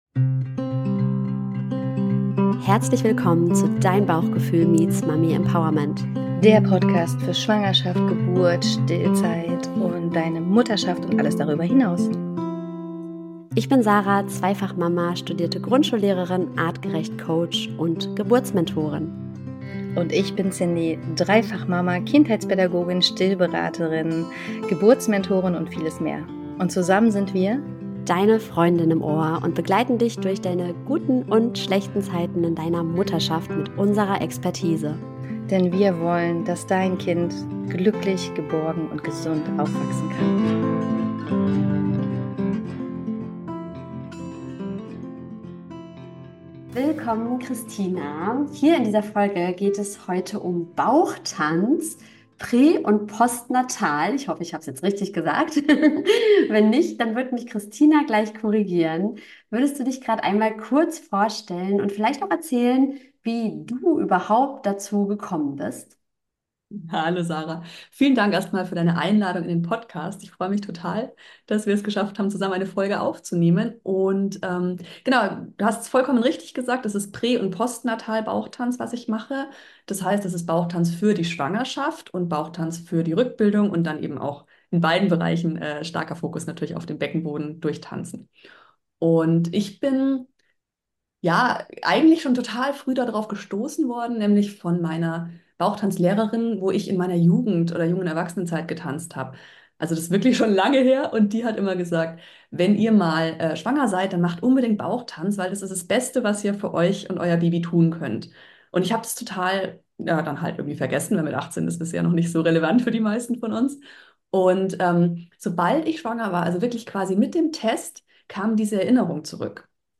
Ein Gespräch über sinnliche Bewegung, mentale Stärke, weibliches Urwissen und die Frage: Warum tanzen wir nicht öfter durch unsere Mutterschaft?